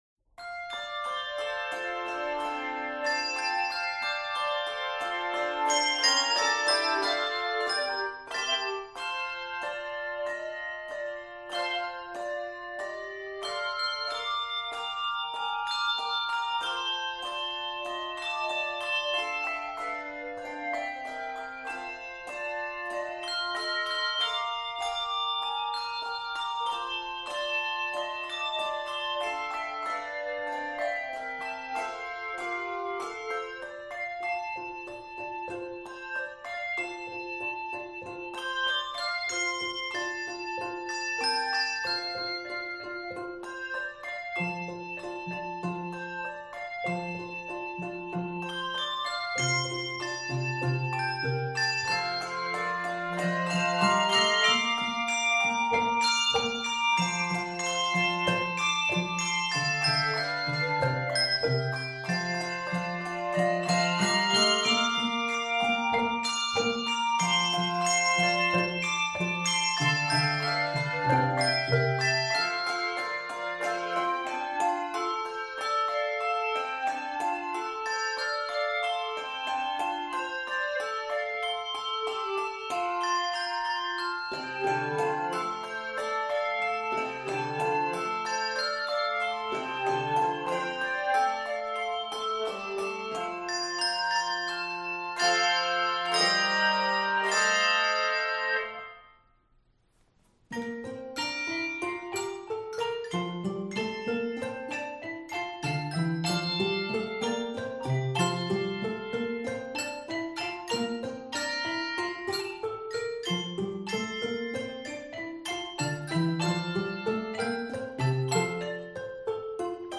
joyful and energetic setting
Key of F Major.